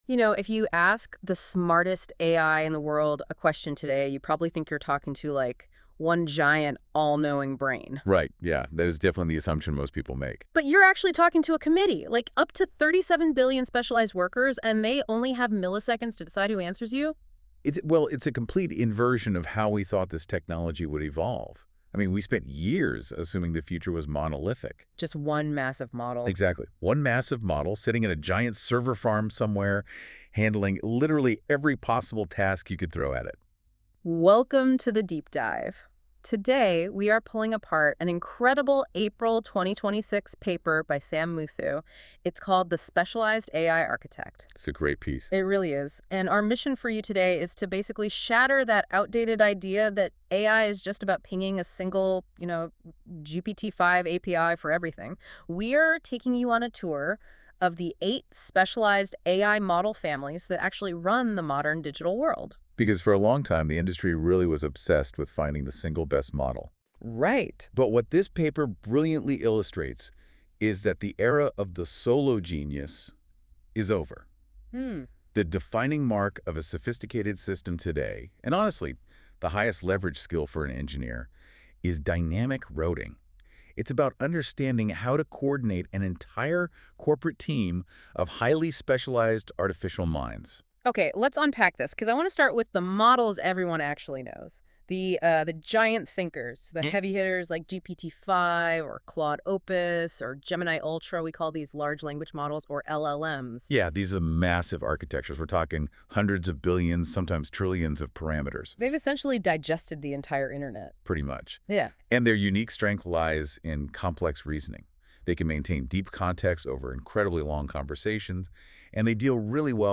A two-host conversation walking through the same journey as this page — the paradigm shift away from monolithic models, the eight specialists that actually run production AI today, and the routing layer that decides which one answers your query. Generated from the source document at the bottom of this page.